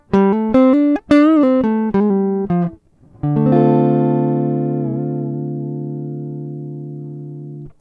Записано все на советский конденсаторный микрофон (или в линию).
звук гитары прямо в комп